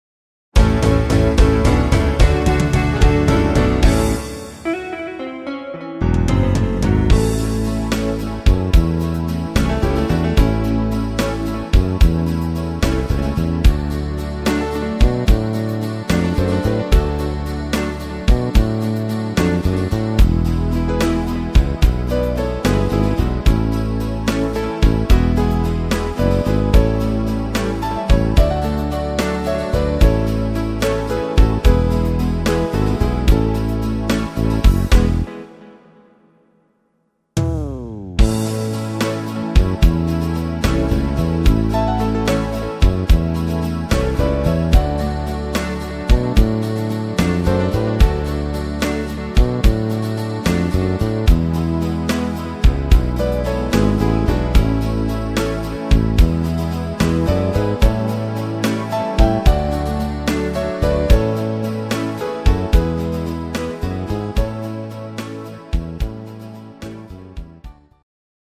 instr. Synthesizer